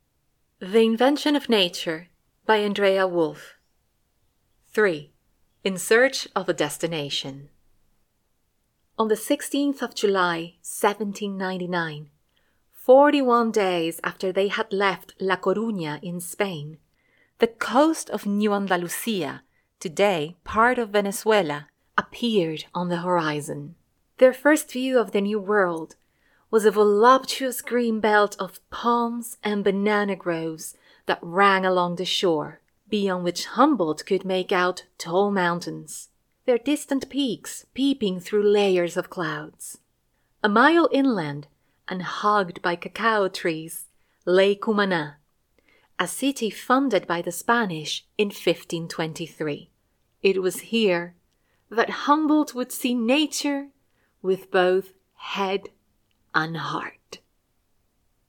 Spanish (Latin American)
Yng Adult (18-29) | Adult (30-50)
Audio Book Recordings